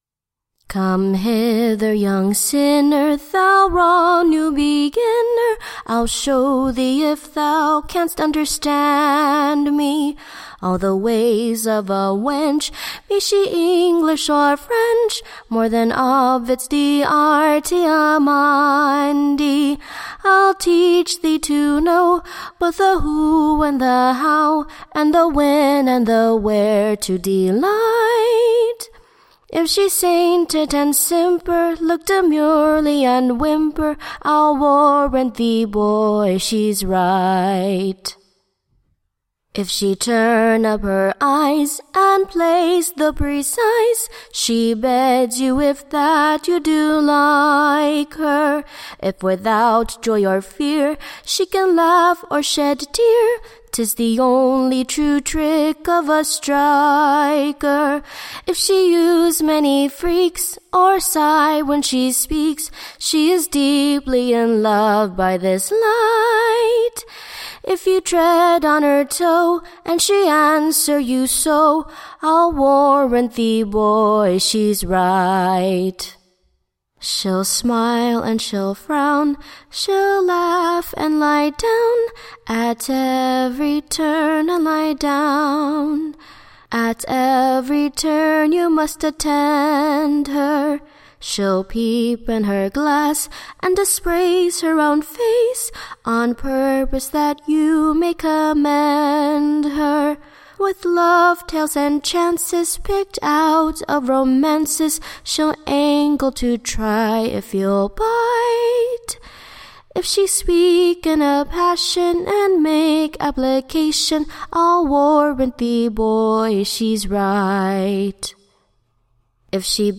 EBBA 33327 - UCSB English Broadside Ballad Archive